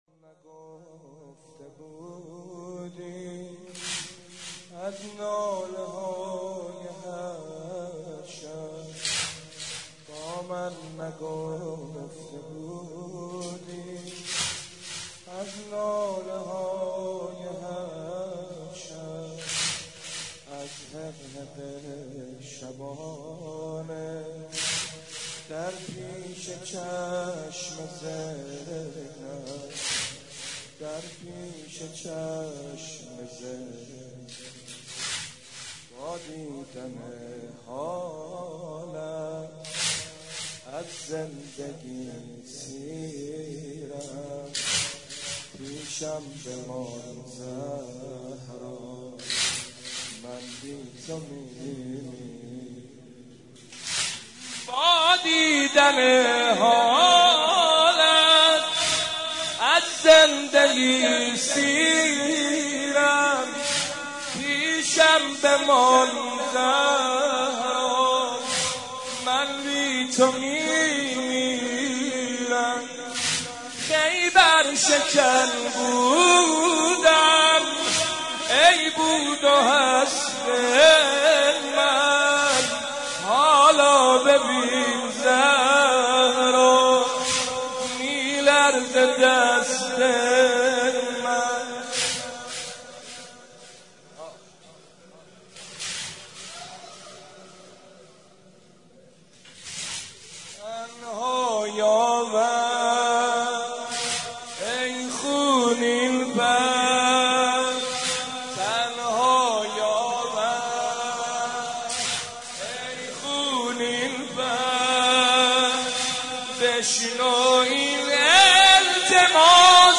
دانلود مداحی با من نگفته بودی از گریه های هر شب - دانلود ریمیکس و آهنگ جدید